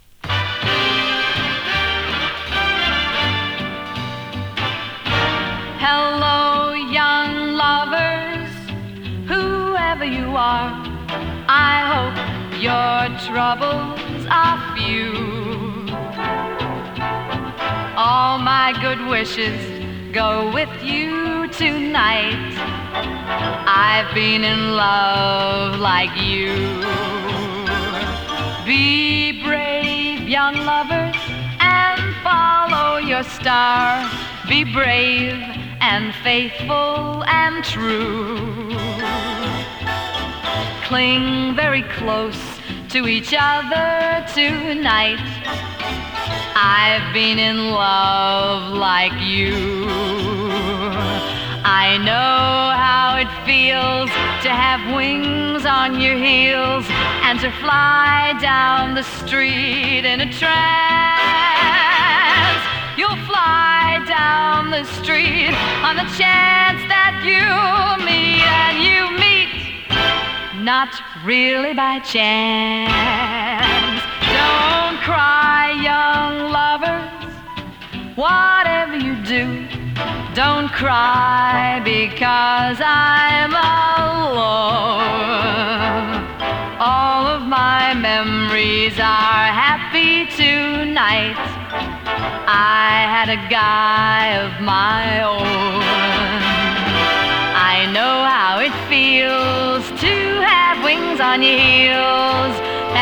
ラテン味
ビッグバンド ジャズボーカル